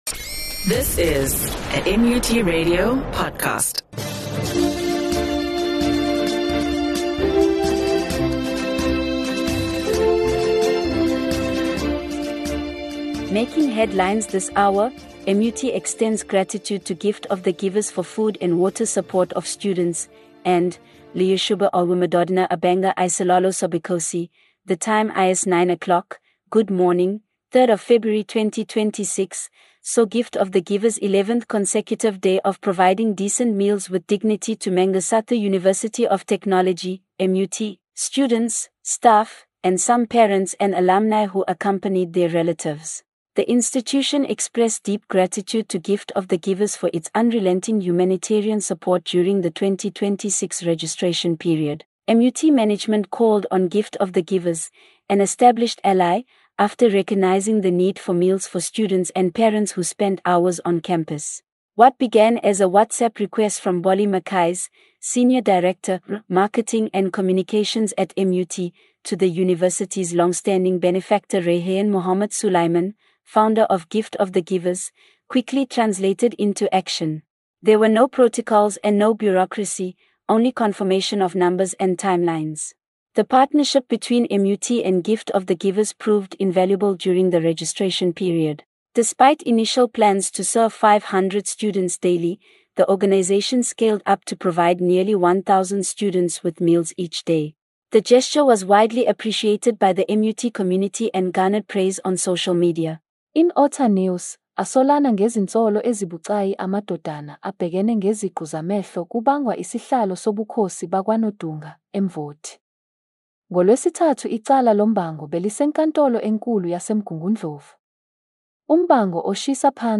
MUT RADIO NEWS AND SPORTS